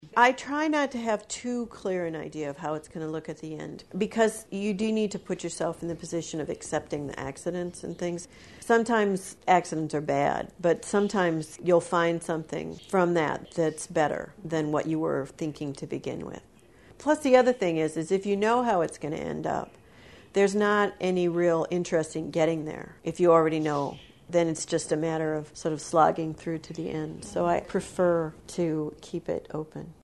The Iowa Women Artists Oral History Project records and preserves the voices of women visual artists in Iowa reflecting on their lives and their artwork.